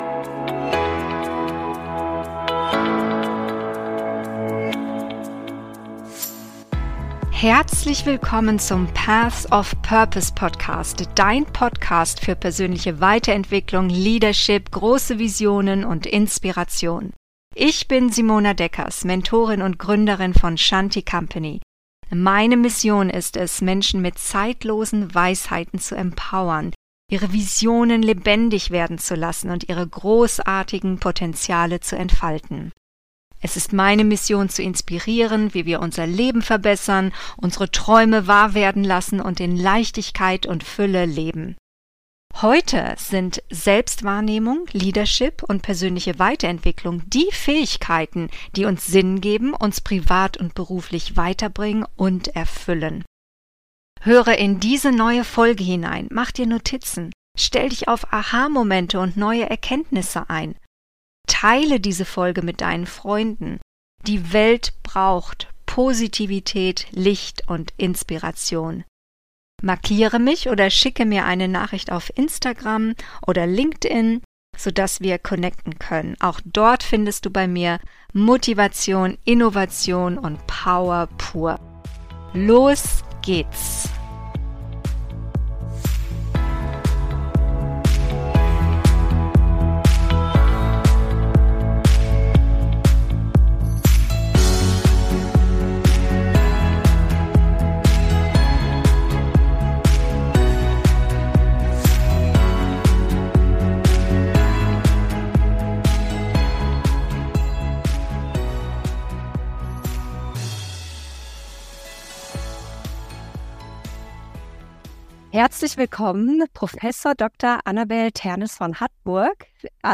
Zukunftsvisionen: Relevanz durch Nachhaltigkeit - Interview